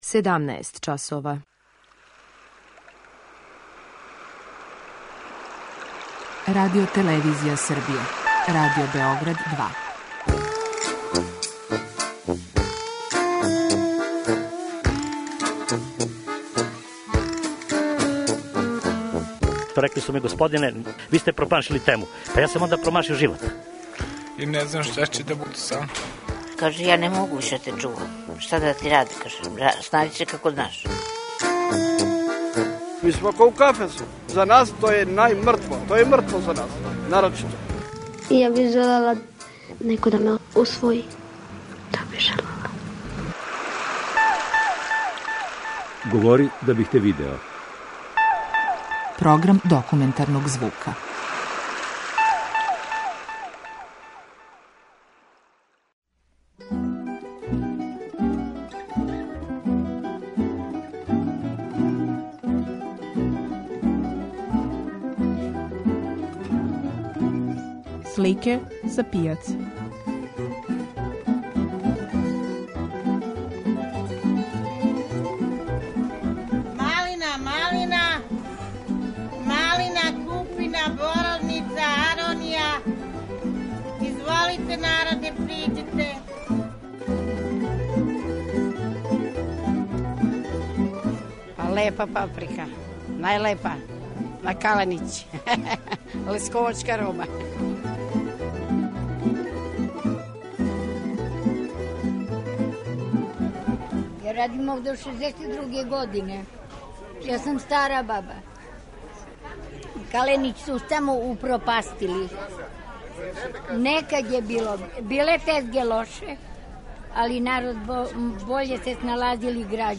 преузми : 10.74 MB Говори да бих те видео Autor: Група аутора Серија полусатних документарних репортажа, за чији је скупни назив узета позната Сократова изрека: "Говори да бих те видео".
У репортажи "Слике са пијаце" обићи ћемо Каленић, Зелени венац и Бајлонијеву пијацу.